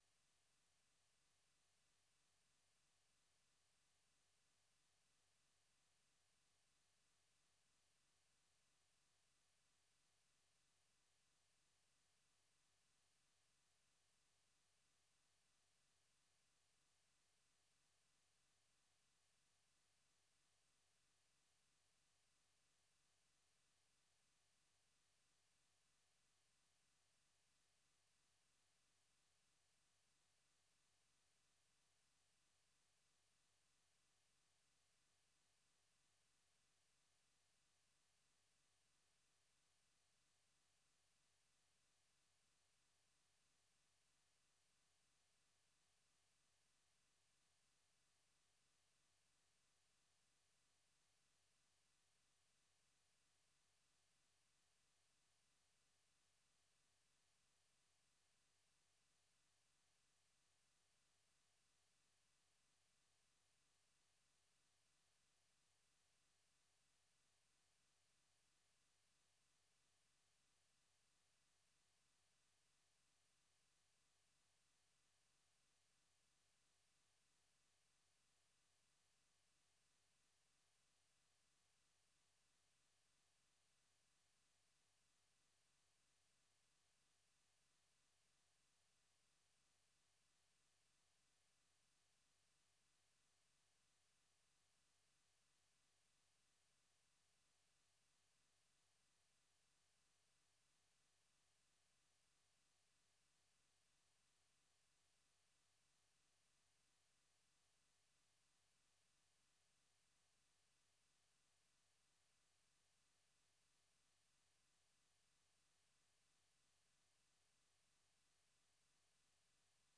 Idaacadda Fiidnimo ee Evening Edition waxaad ku maqashaan wararkii ugu danbeeyey ee Soomaaliya iyo Caalamka, barnaamijyo iyo wareysiyo ka turjumaya dhacdooyinka waqtigaasi ka dhacaya daafaha Dunida.